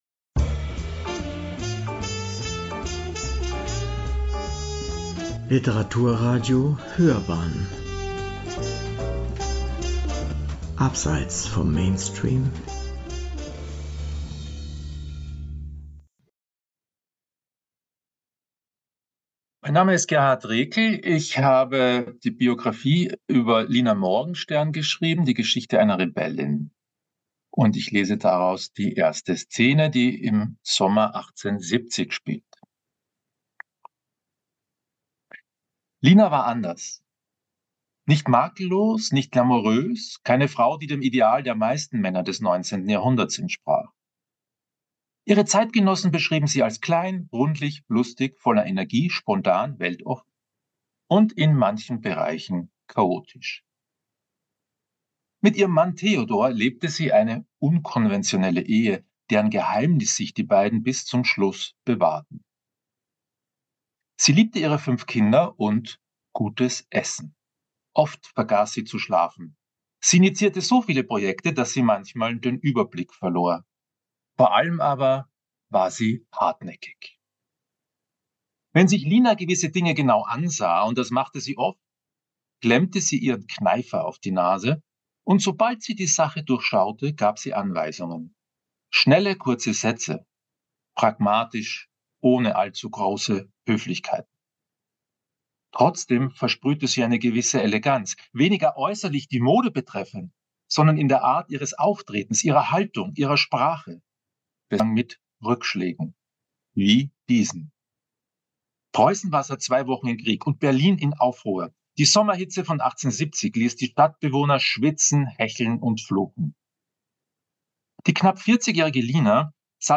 EJEB-Lina-Morgenstern-1-Lesung-GRK-upload-.mp3